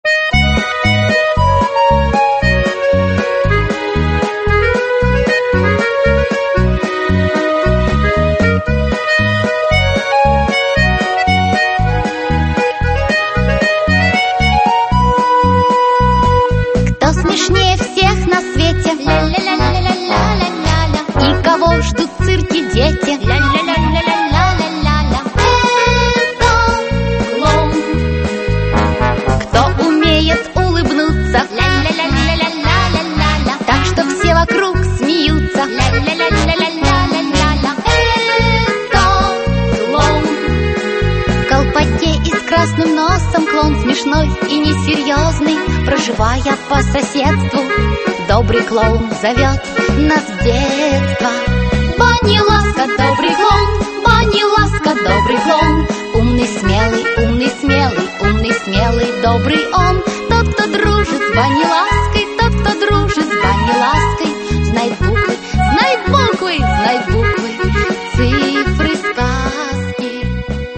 Аудиокнига Веселые уроки Баниласки. Путешествия. Страны. Транспорт. Правила дорожного движения | Библиотека аудиокниг